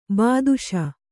♪ bāduśa